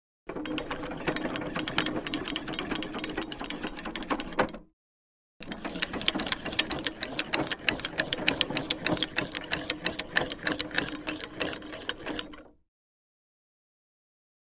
Howitzer Artillery Cannon: Aiming Movement; WW1 Type Cannon Being Adjusted To Be Fired. Fast Cranking And Metal Squeaks. Starts And Stops Two Times. Close Up Perspective.